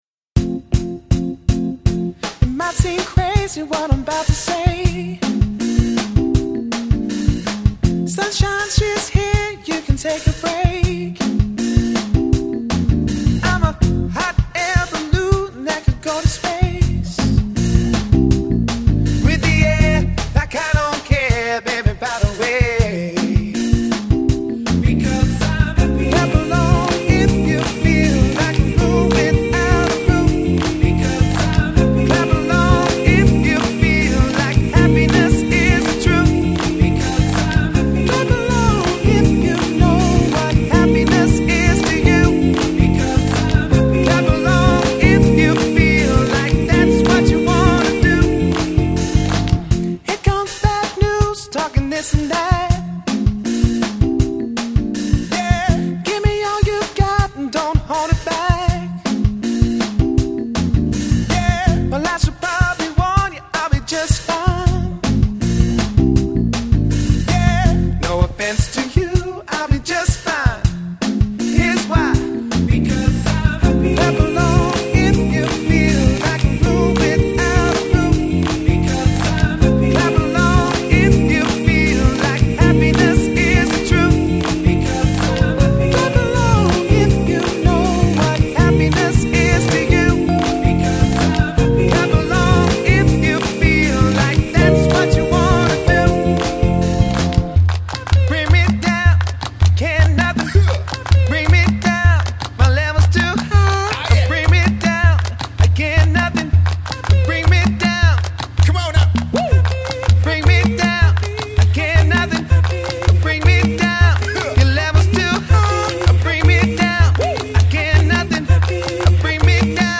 • Performing funk, soul, hip hop and garage music